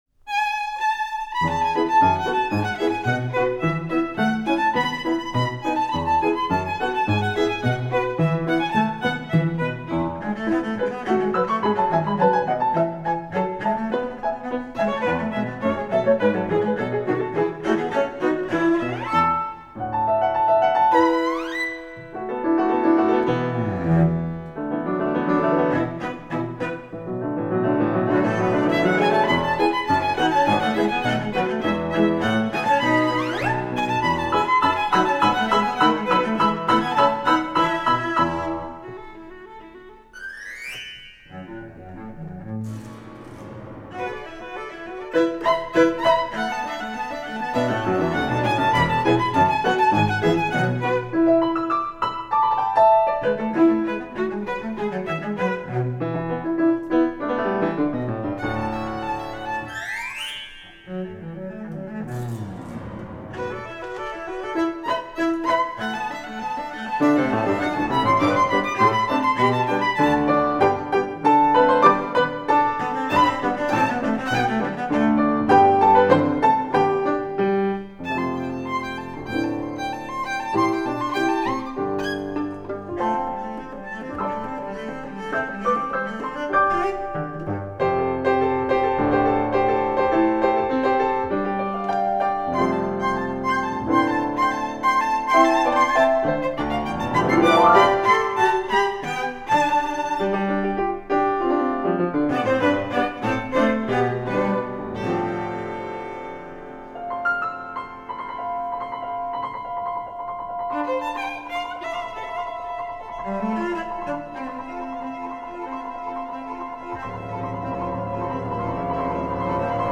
for Piano Trio (or Clarinet, Cello, and Piano) (1989)